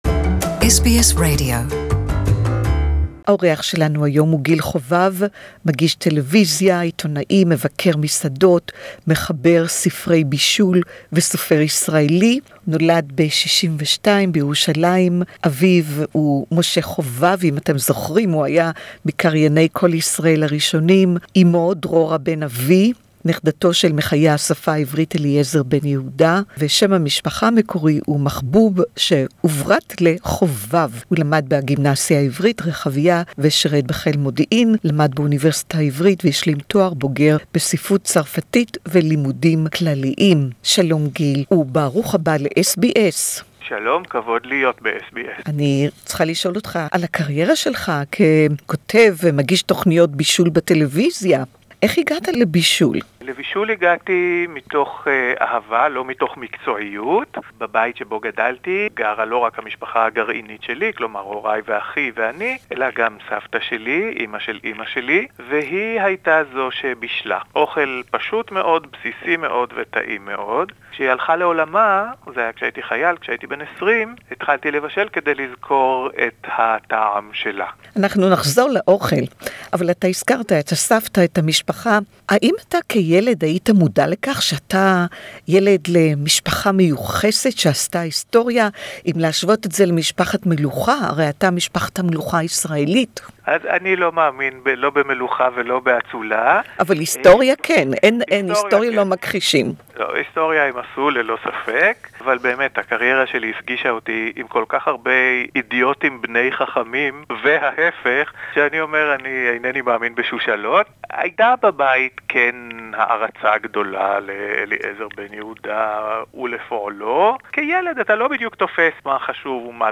Gil Hovav is proud of his great grandfather Eliezer Ben Yehuda (Hebrew interview)
Our guest today is Gil Hovav. His great grandfather was the legendary Eliezer Ben Yehuda, the driving force behind the revival of the Hebrew language.